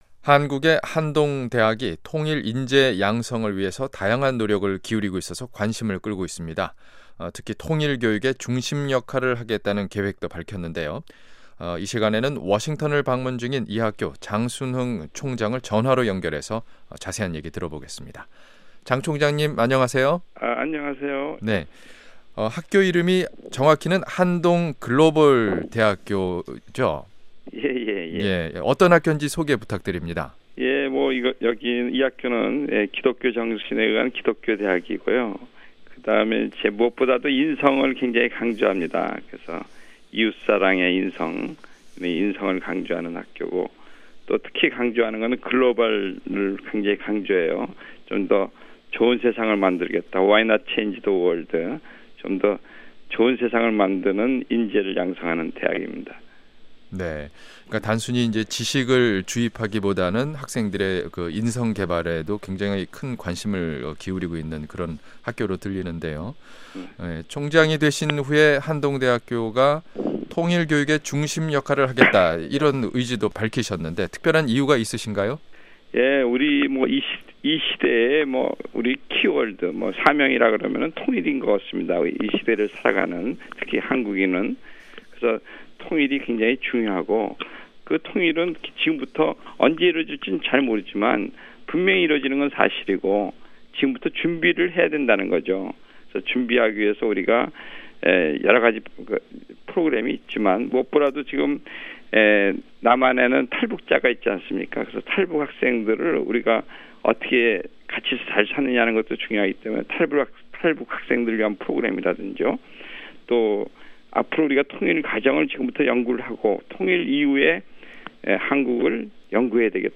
전화로 연결해 인터뷰했습니다.